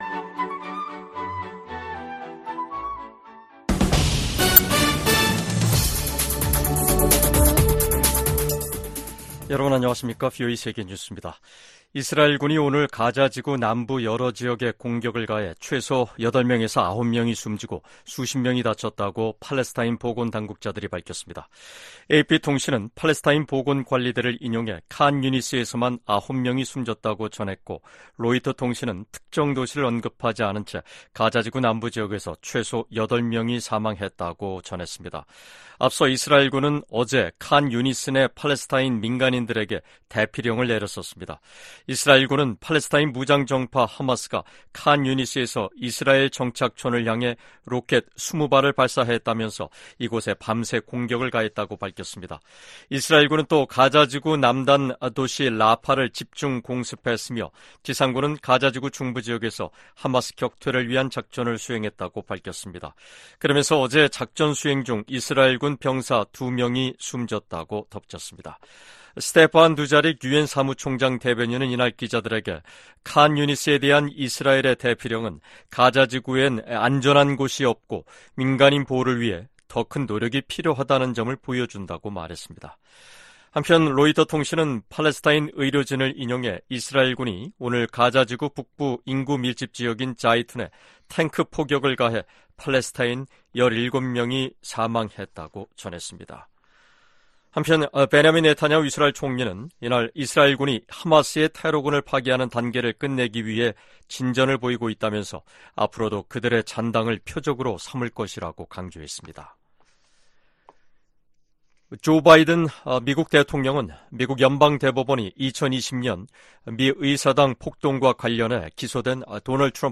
세계 뉴스와 함께 미국의 모든 것을 소개하는 '생방송 여기는 워싱턴입니다', 2024년 7월 2일 저녁 방송입니다. '지구촌 오늘'에서는 이스라엘군이 팔레스타인 가자지구 칸유니스 지역에 대피 명령 후 공격한 소식 전해드리고 '아메리카 나우'에서는 미국 연방대법원이 대통령의 공적 행위에 대한 면책 권한은 전임 대통령도 적용된다며, 도널드 트럼프 전 대통령의 대선 결과 뒤집기 시도 혐의에 대한 면책특권 적용 여부를 하급심 법원으로 넘긴 소식 전해드립니다.